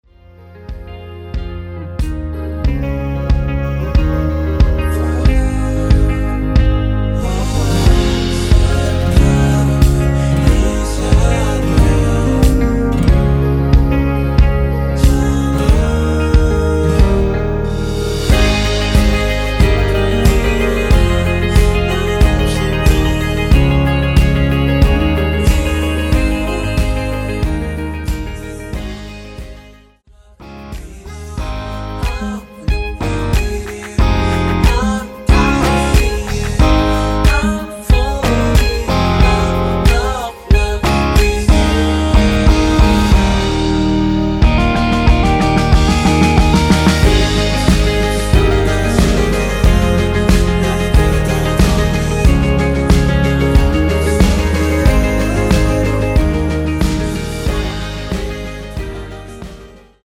원키에서(-1)내린 멜로디와 코러스 포함된 MR입니다.(미리듣기 확인)
Ab
앞부분30초, 뒷부분30초씩 편집해서 올려 드리고 있습니다.